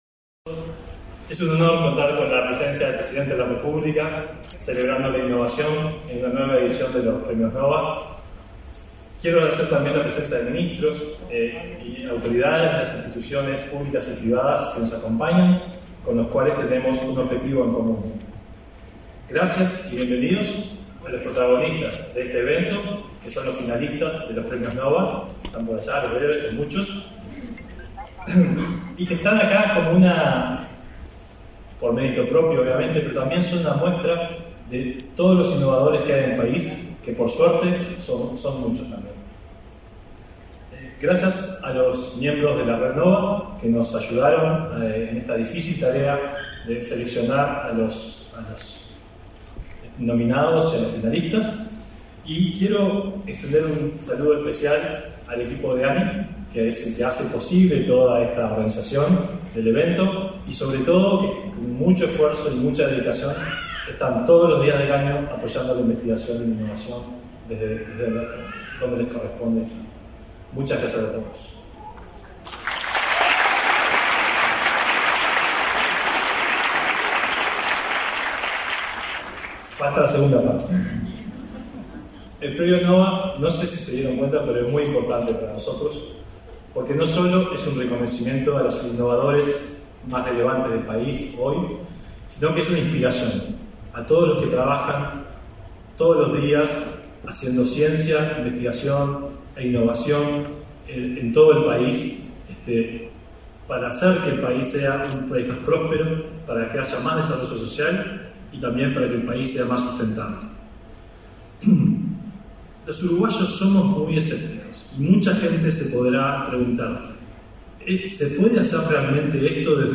Conferencia de prensa por la entrega de los premios NOVA de la Agencia Nacional de Investigación e Innovación